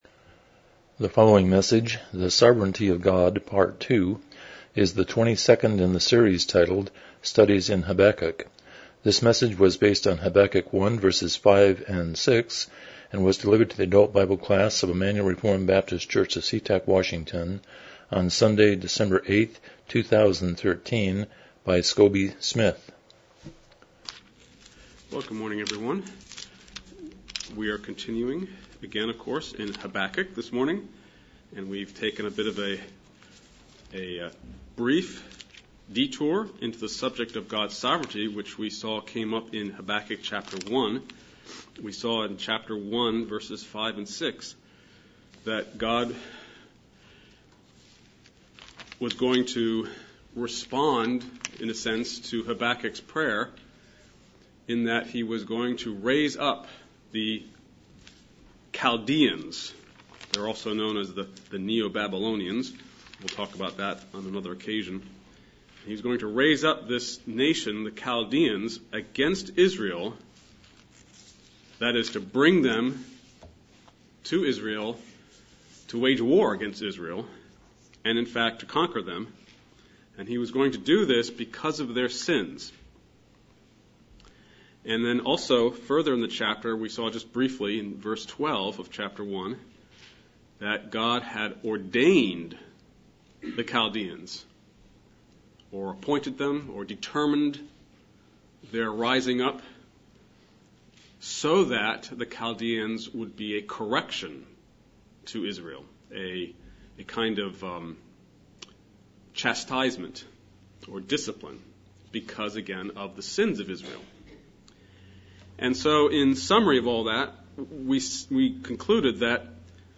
Habakkuk 1:5-6 Service Type: Sunday School « 21 The Sovereignty of God